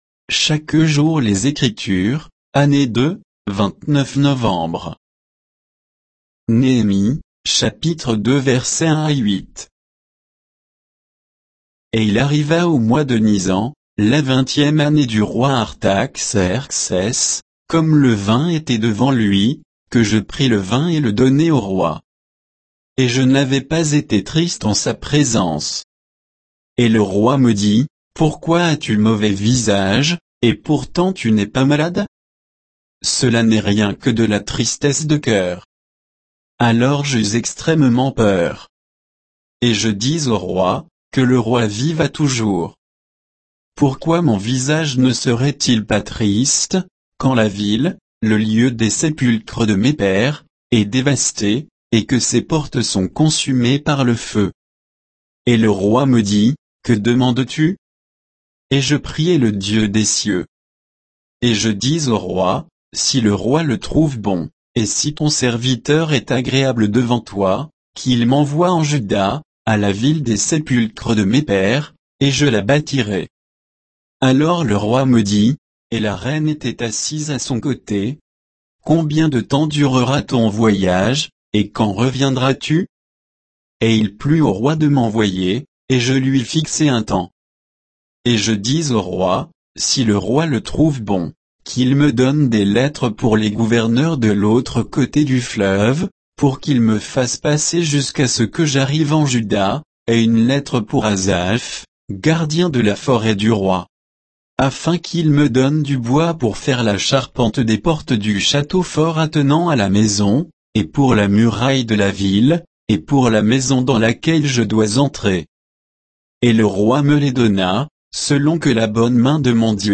Méditation quoditienne de Chaque jour les Écritures sur Néhémie 2